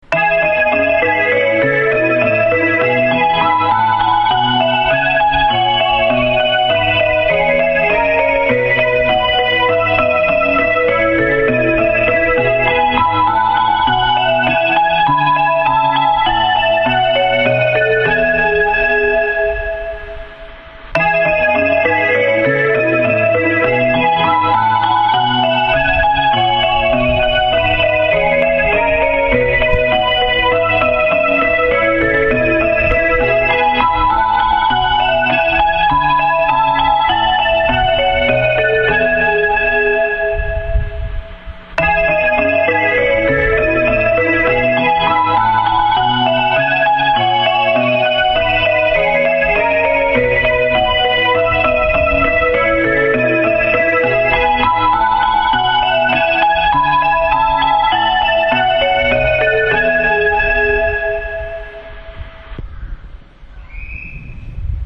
旧発車メロディ
ＴＯＡ三角型（発車メロディ）